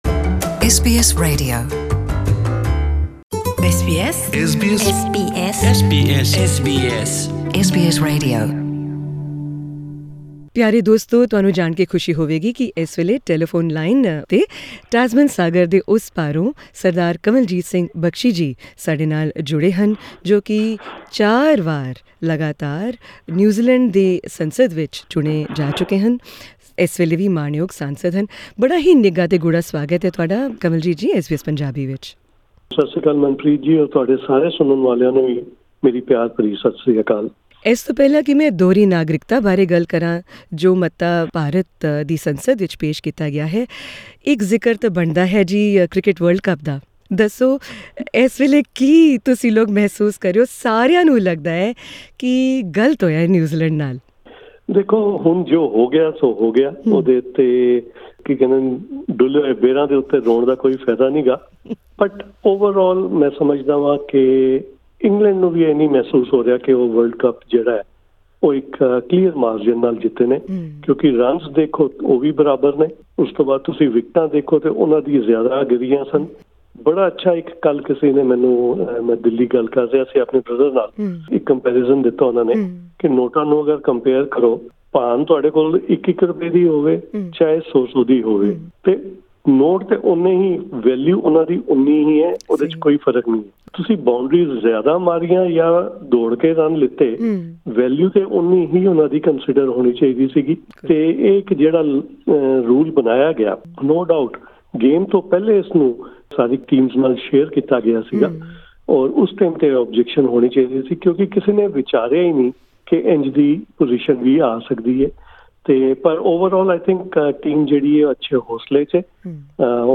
Kanwaljit Singh Bakshi, MP speaks to SBS Punjabi from Auckland.